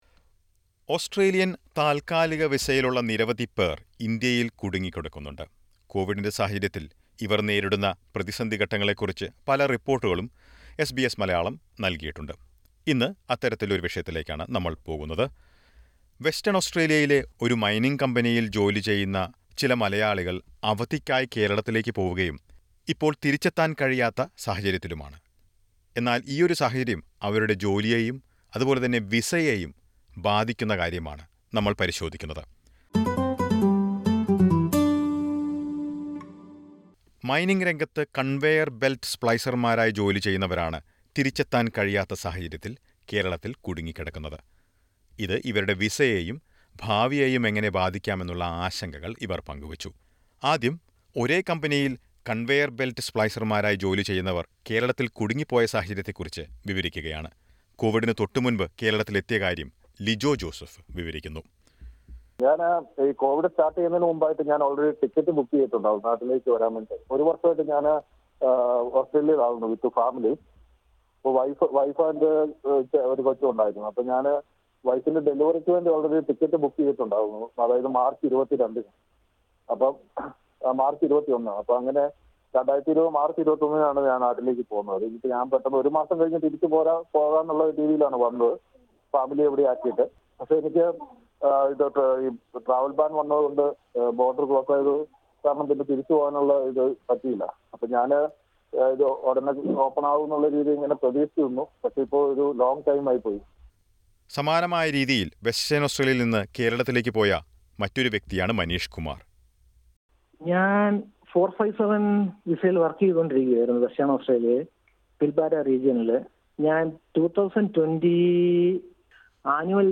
A few temporary residents stuck in India fear their Australian dream is over if they don't get back to Australia in the next two months. Listen to a report.